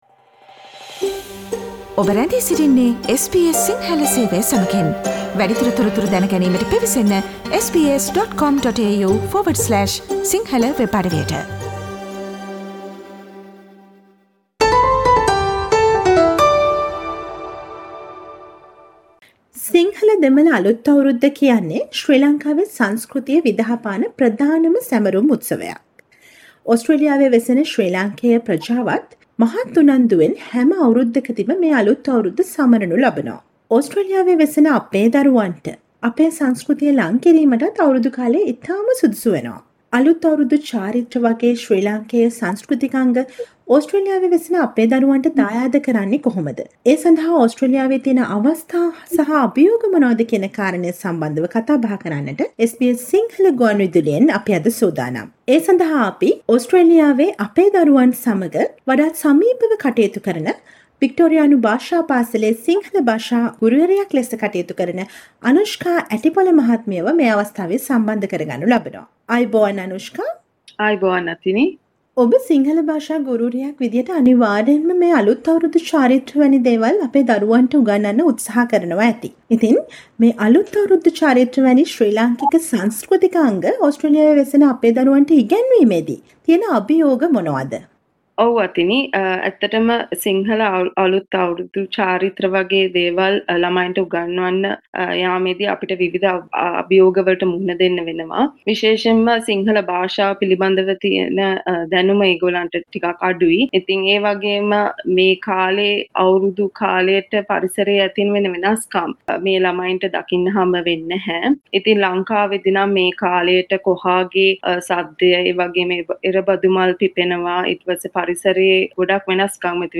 SBS සිංහල ගුවන්විදුලි සේවය සිදු කළ මෙම සාකච්ඡාවට සවන්දෙන්න